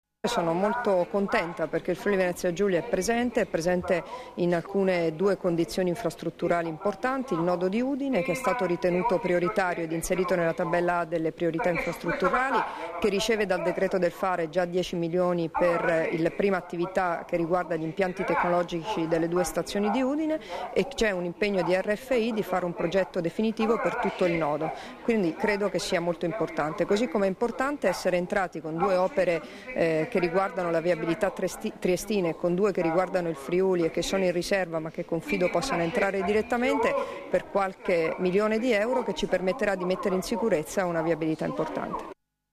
Ascolta le dichiarazioni di Debora Serracchiani (Formato MP3) [681KB]